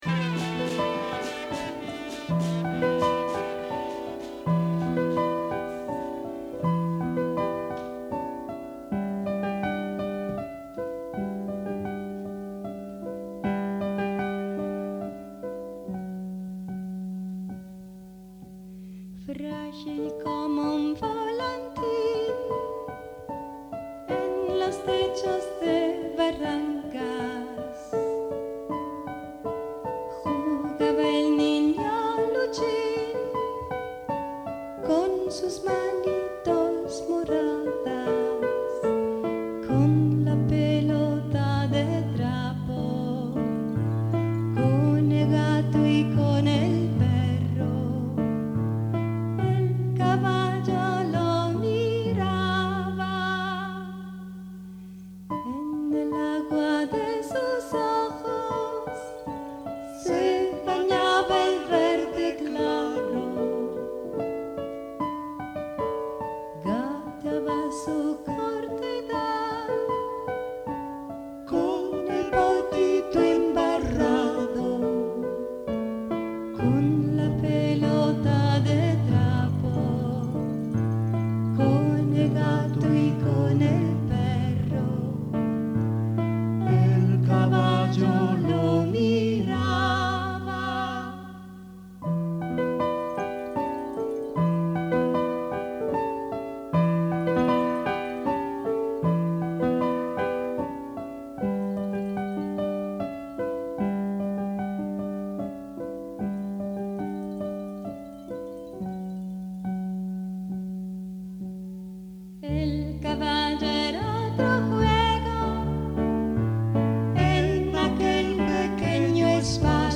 voce, chitarra, tiple, charango
sax tenore e soprano. flauti
Registrato a Milano - CSOA Leoncavallo il 7 marzo 2000